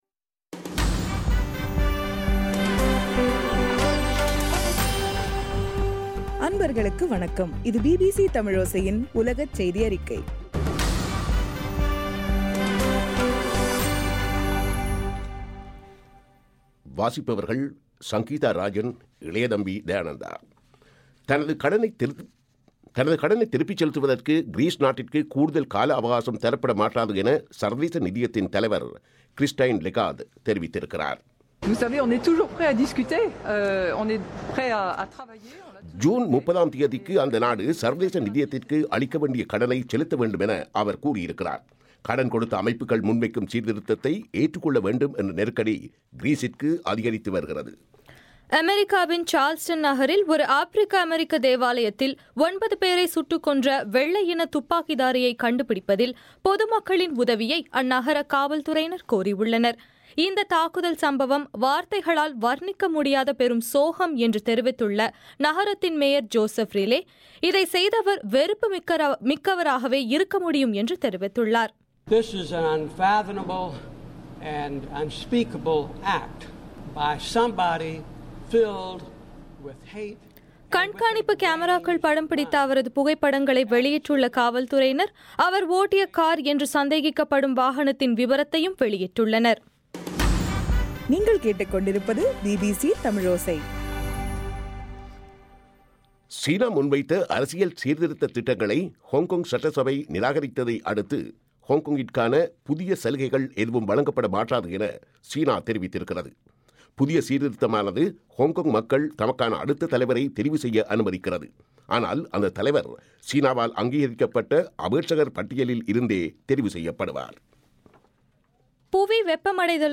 பிபிசியின் உலகச் செய்தியறிக்கை, ஜூன் 18